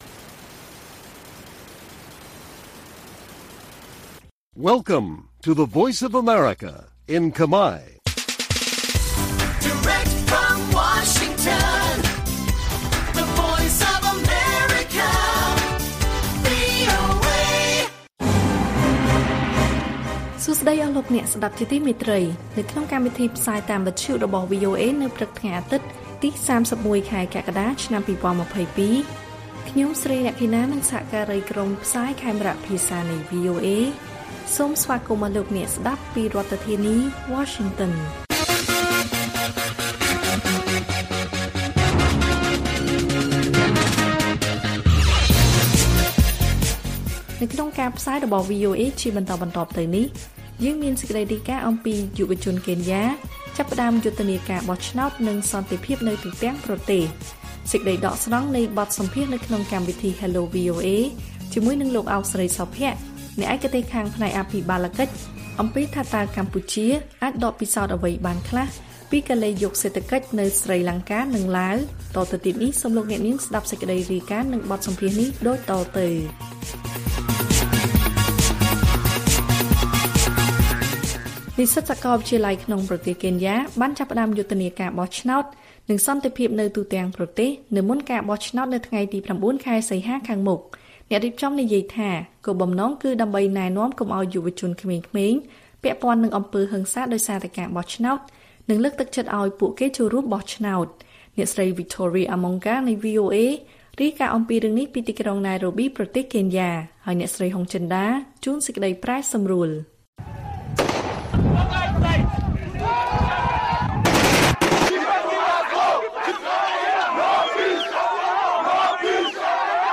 ព័ត៌មានពេលព្រឹក ៣១ កក្កដា៖ បទសម្ភាសន៍ VOA អំពី«ថាតើកម្ពុជាអាចដកពិសោធន៍អ្វីបានខ្លះពីកលិយុគសេដ្ឋកិច្ចនៅស្រីលង្កានិងឡាវ»